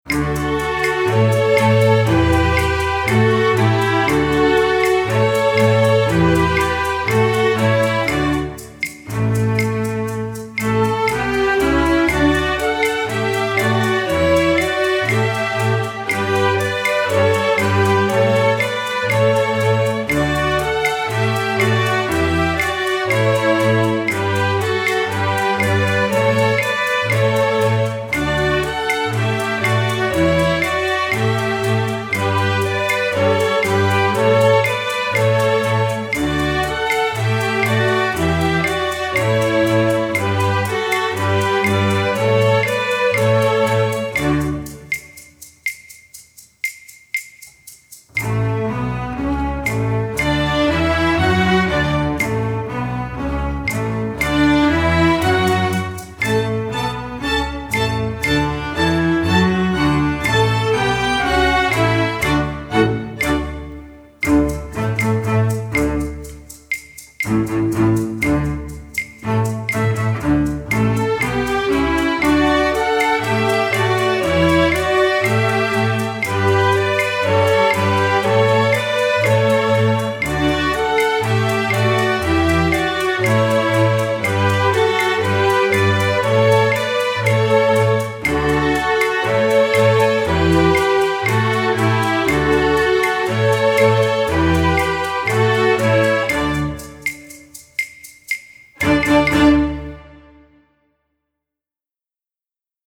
latin, multicultural